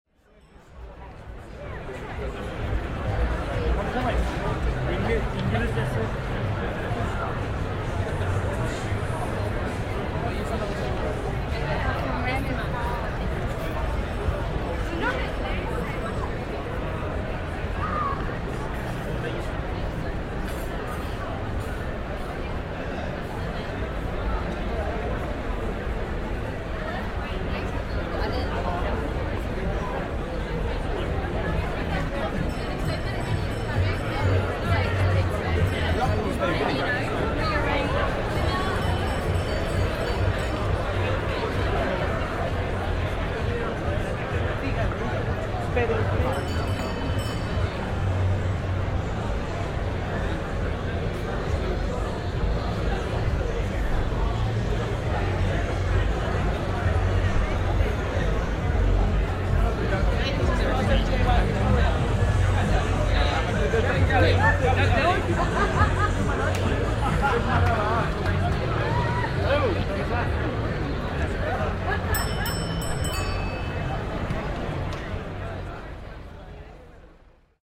Rickshaw bells, Soho